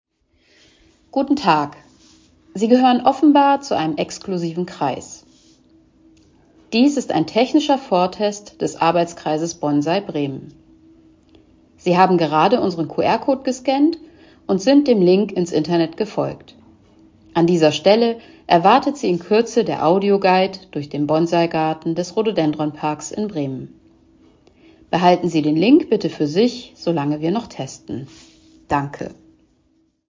Audioguide für den Bonsaigarten im Rhododendronpark